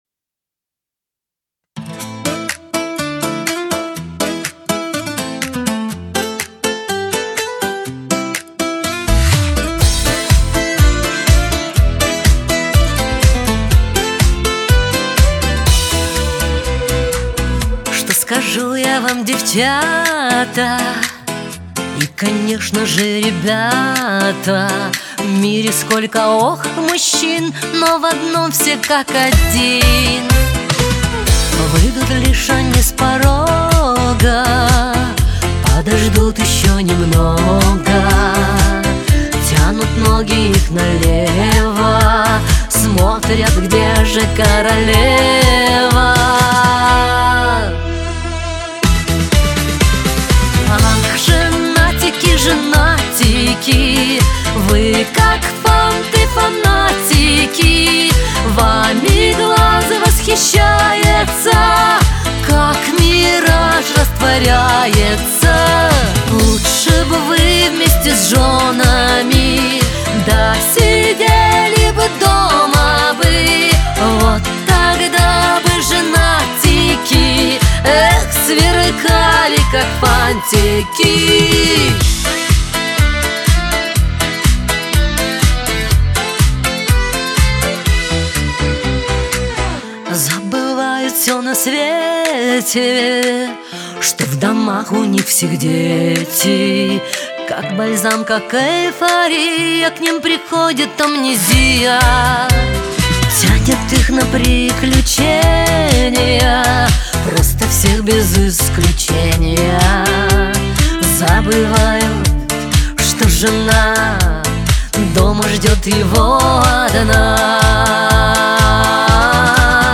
диско
Кавказ поп
эстрада